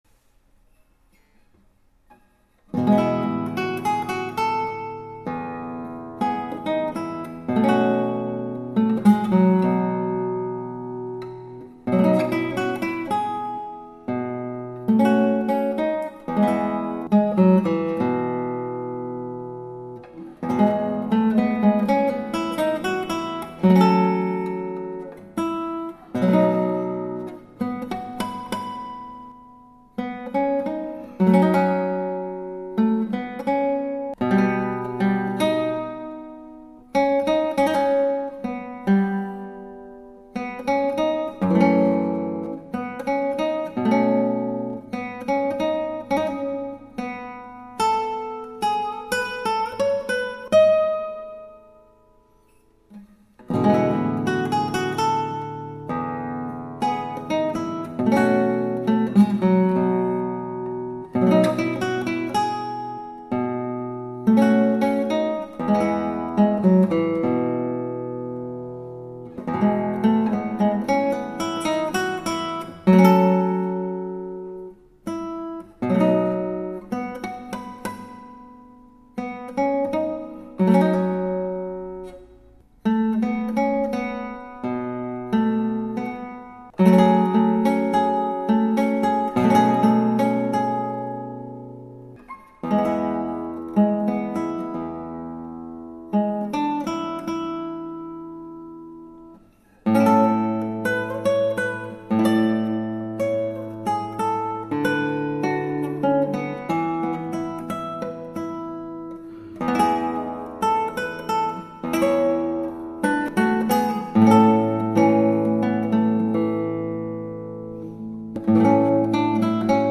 以上ギターはアルカンヘル